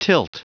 Prononciation du mot tilt en anglais (fichier audio)
Prononciation du mot : tilt